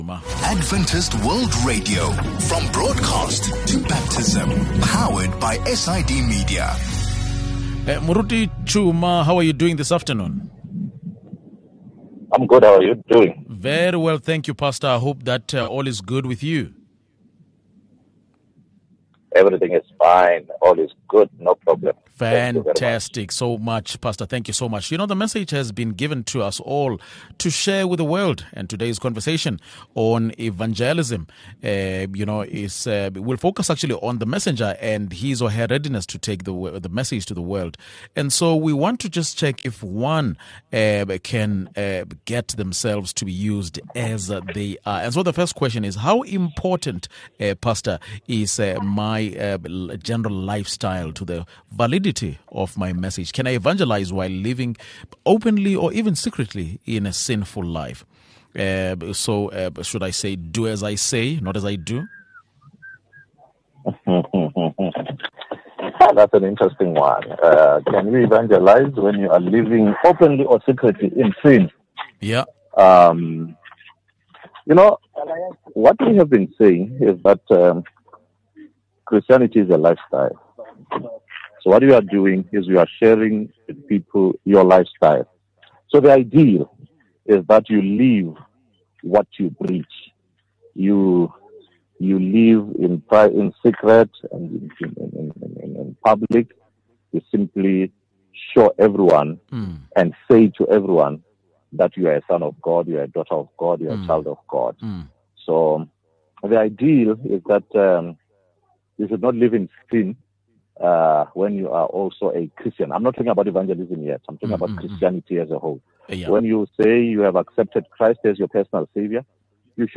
Today’s conversation on evangelism will focus on the messenger, and his/her readiness to take the word to the world. Can you or I be used by God, just as we are?